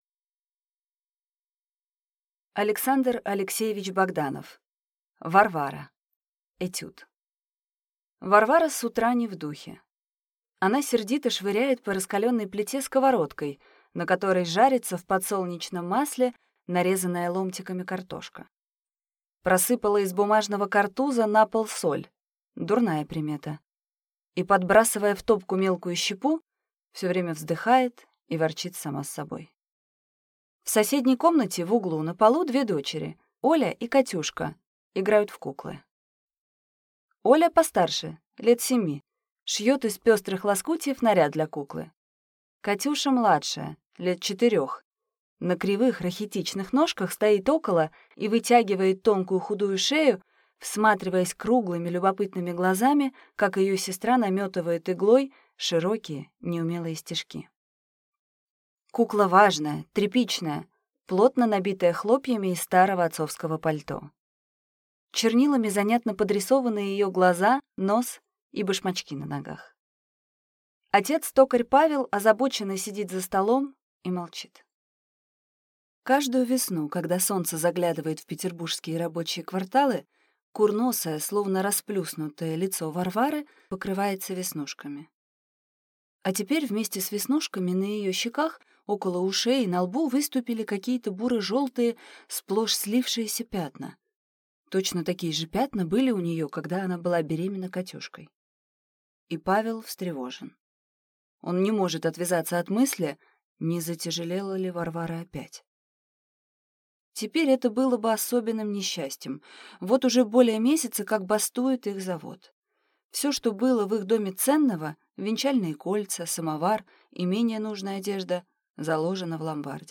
Аудиокнига Варвара | Библиотека аудиокниг